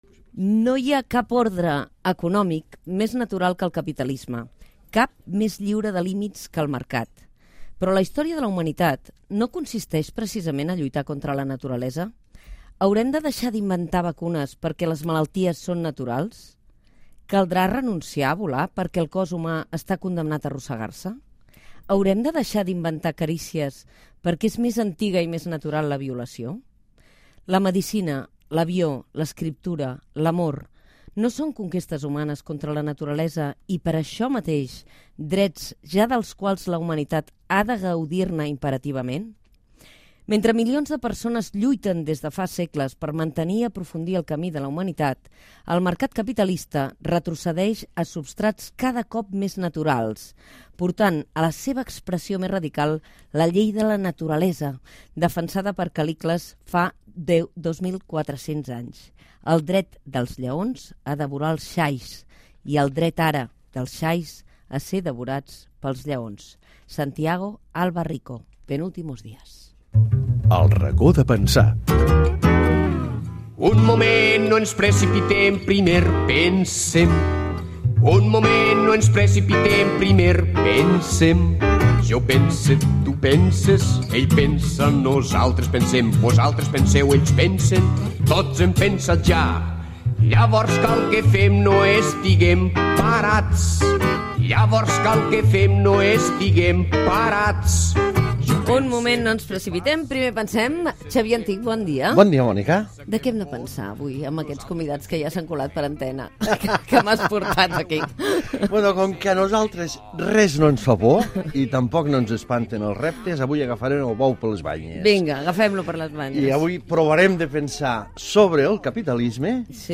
Secció "El racó de pensar" reflexiona sobre els efectes del capitalisme amb el professor Xavier Antich, el periodista i polític David Fernàndez i el filòsof Santiago Alba Rico
Info-entreteniment